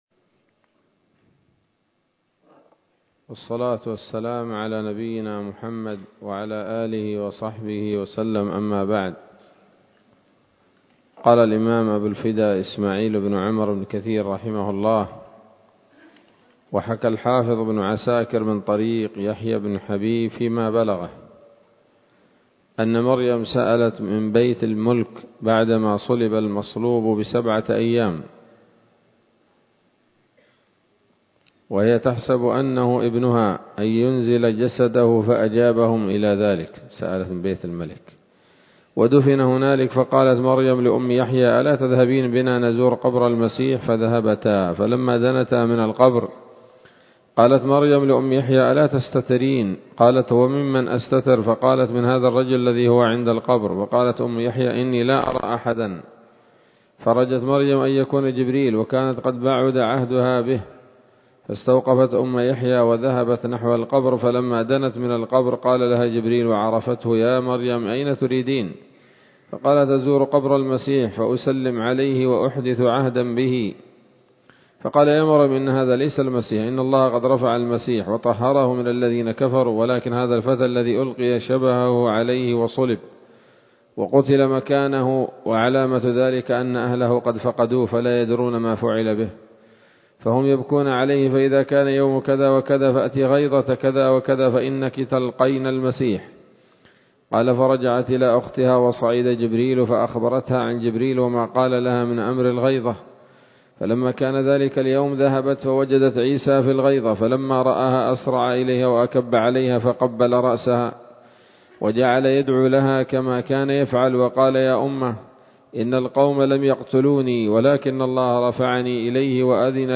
‌‌الدرس الثاني والخمسون بعد المائة من قصص الأنبياء لابن كثير رحمه الله تعالى